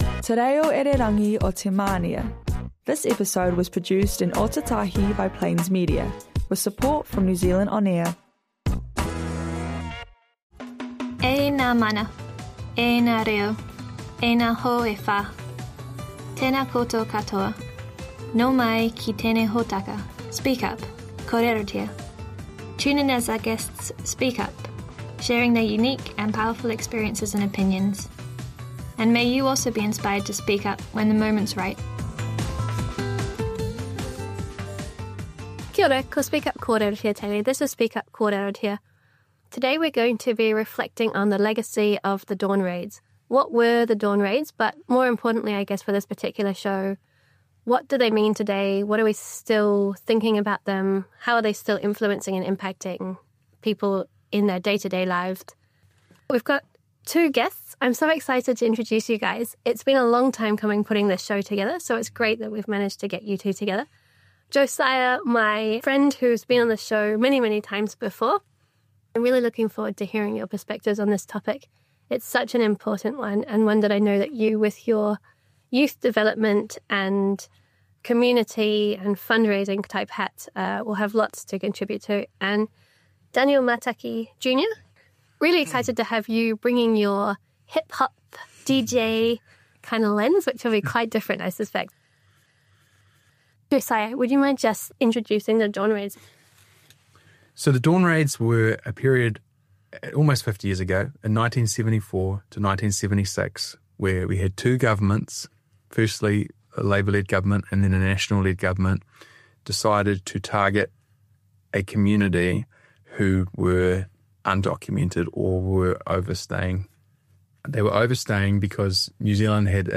Episode of human rights radio show with recommended reading on the topic of the Dawn Raids and the Polynesian Panthers.